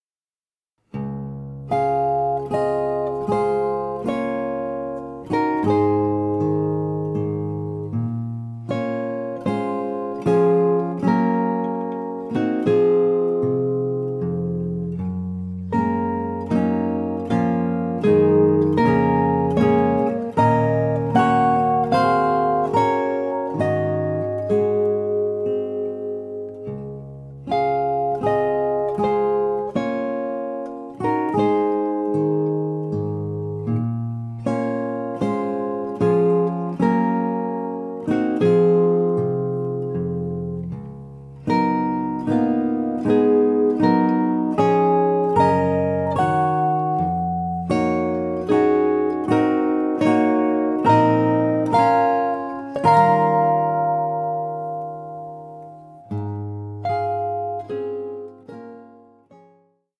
Molto Cantando